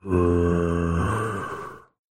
Minecraft Zombie Sound Effect Free Download